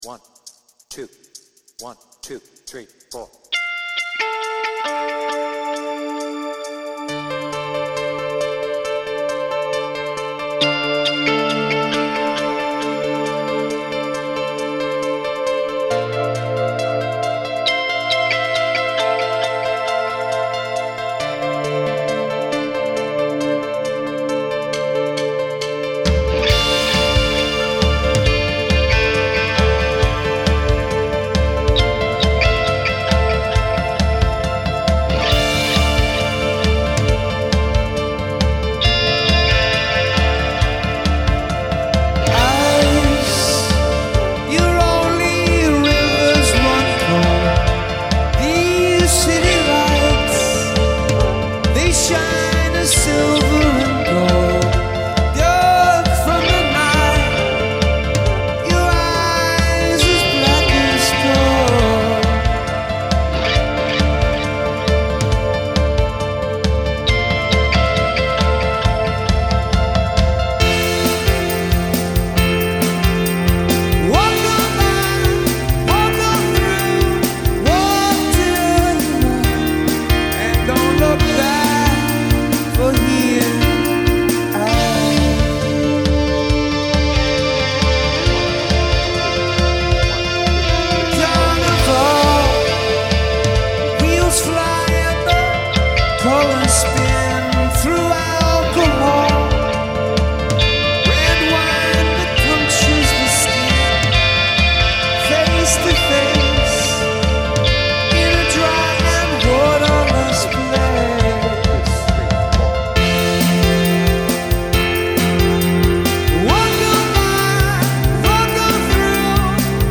BPM : 136